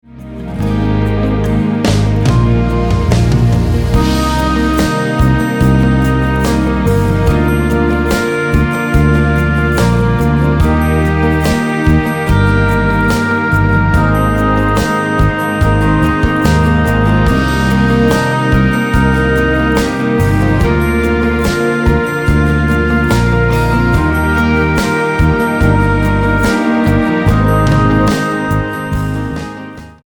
Instrumental-CD